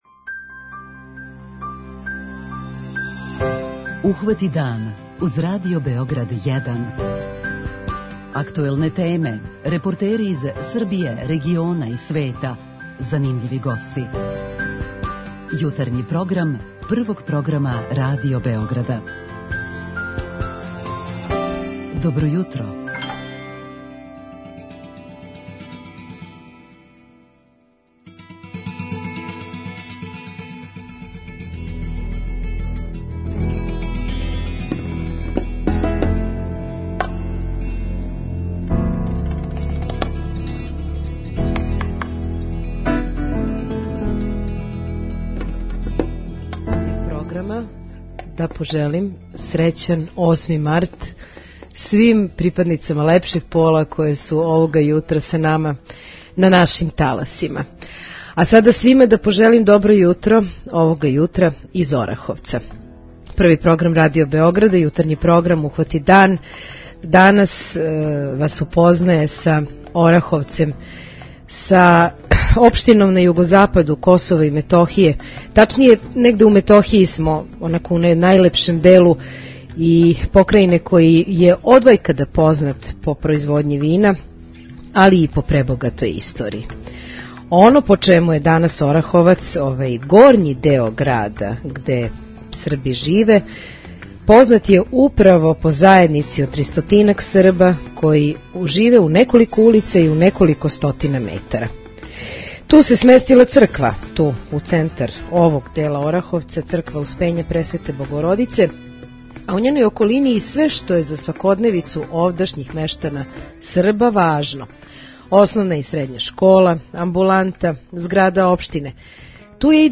Овога јутра Ухвати дан уживо са Косова и Метохије! Емисију емитујемо из Ораховца.